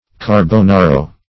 carbonaro - definition of carbonaro - synonyms, pronunciation, spelling from Free Dictionary
Carbonaro \Car`bo*na"ro\, n.; pl. Carbonari. [It., a coal